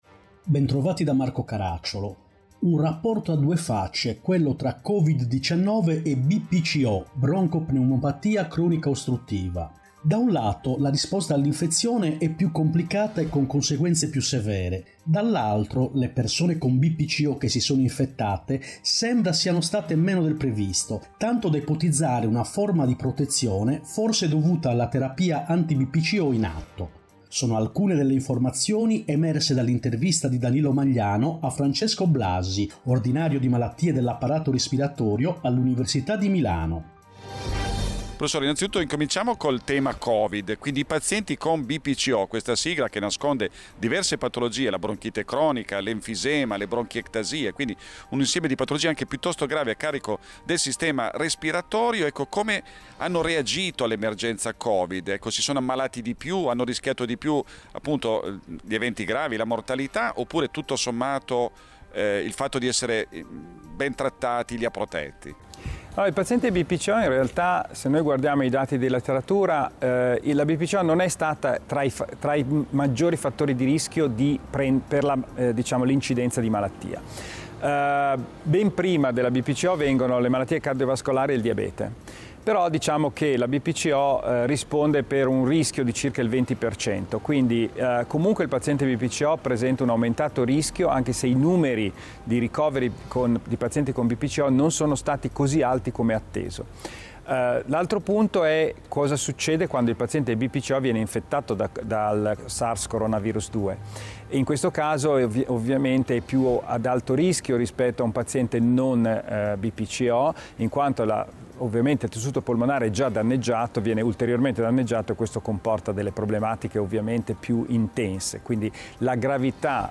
Tasto destro per scaricare la puntata: Puntata con sigla Puntata senza sigla